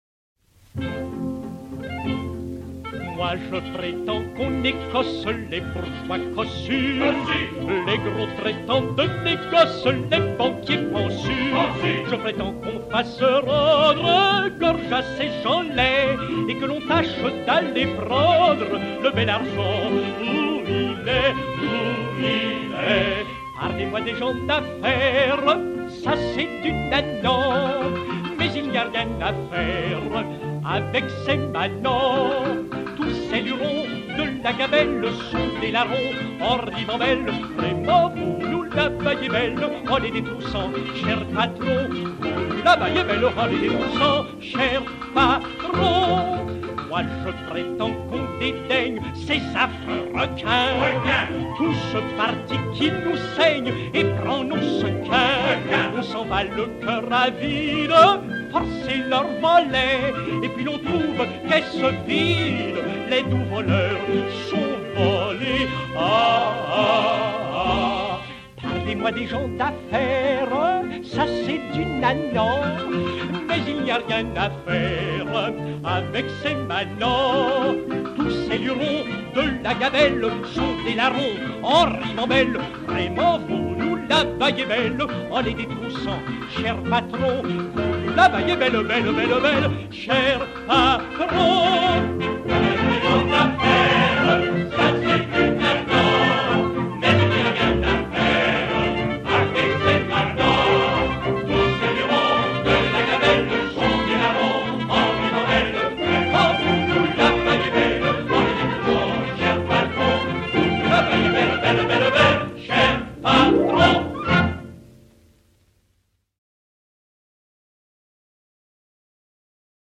Chœurs